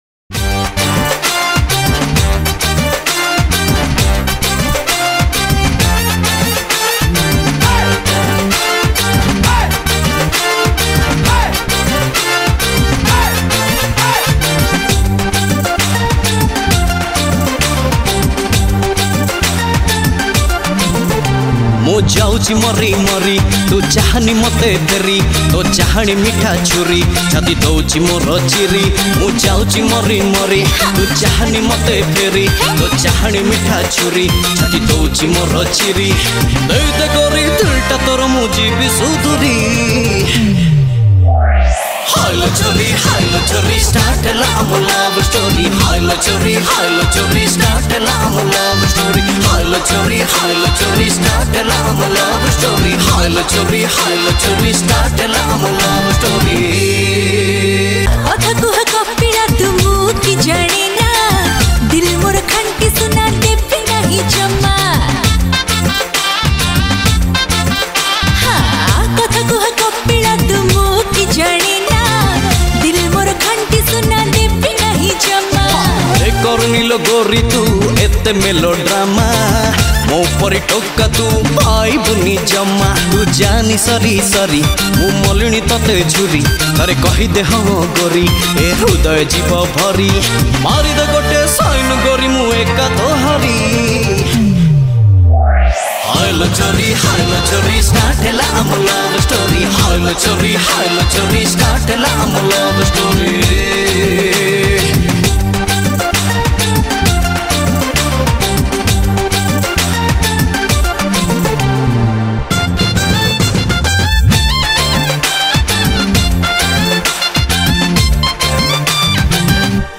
Odia Dance Song